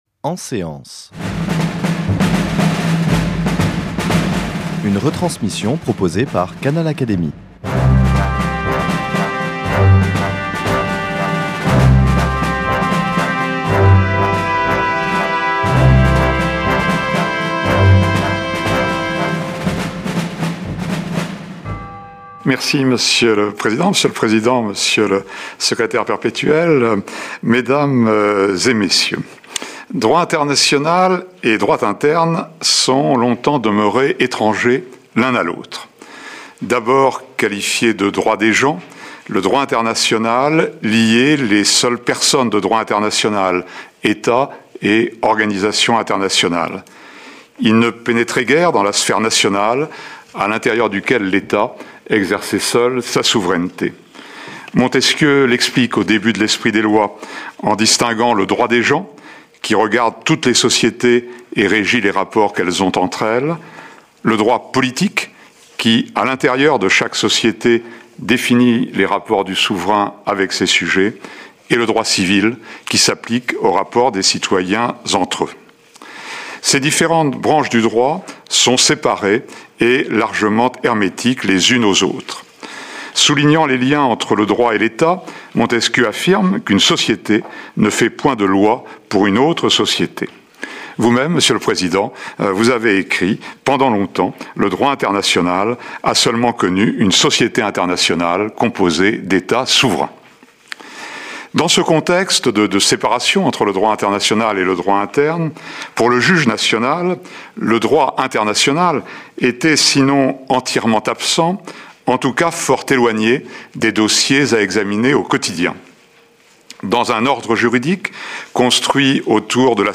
L’oratrice a dressé une liste de quatre facteurs qui sont apparus durant les dernières décennies et qu’elle estime devoir être pris en considération en raison de leur incidence forte sur le droit de la famille.